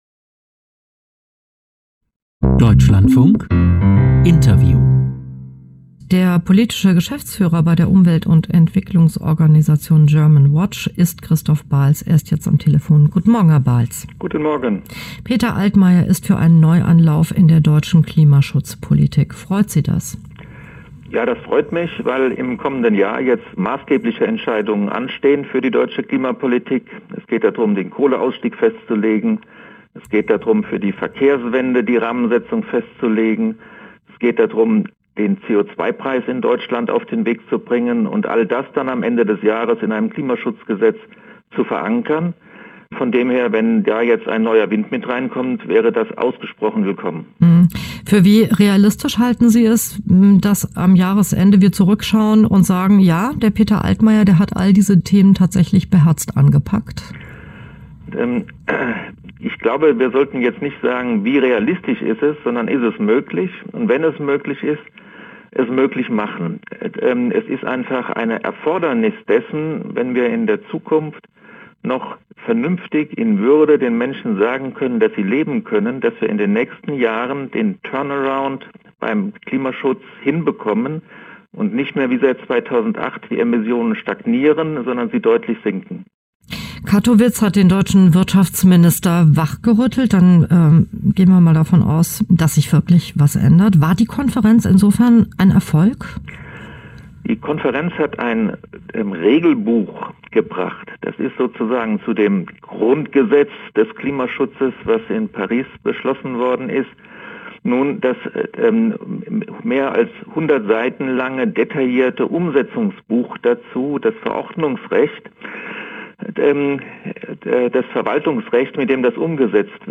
der dem Deutschlandfunk am 17.12.2018 ein Interview gab